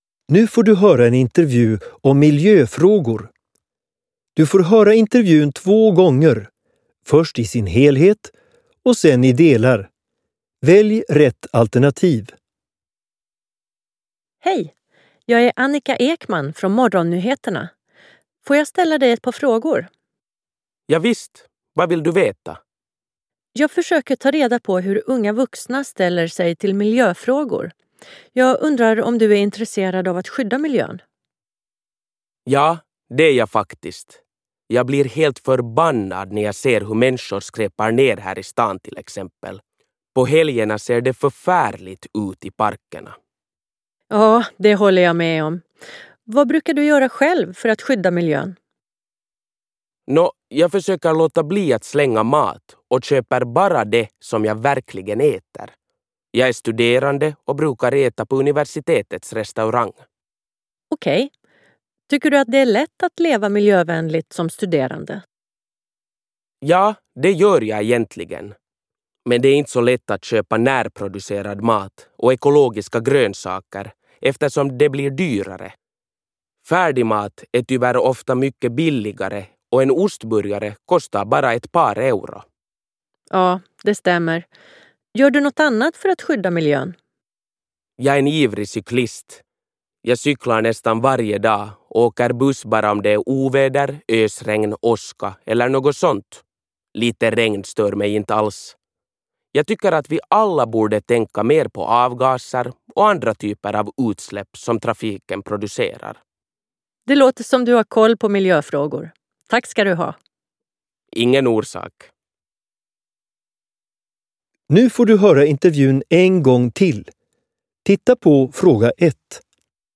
08_Hallbar_utveckling_Intervju_Miljofragor.mp3